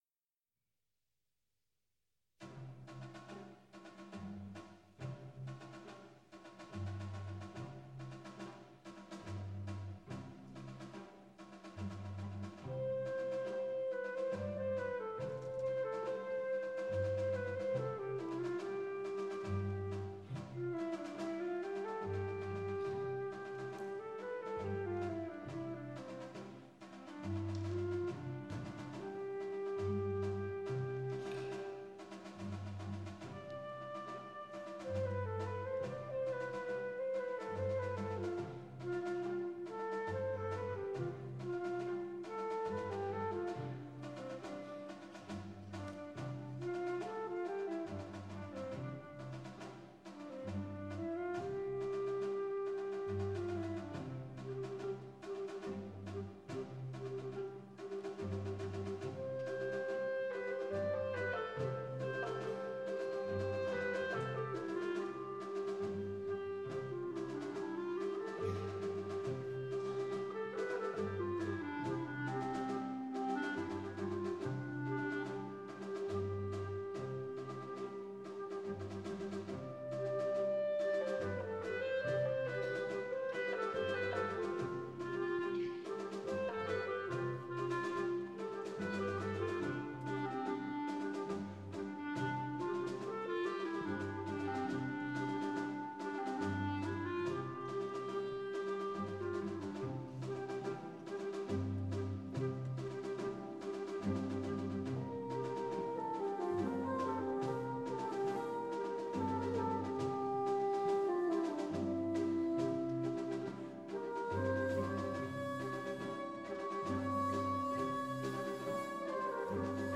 Konzert live, 29. November 2014 (Stadtcasino Basel)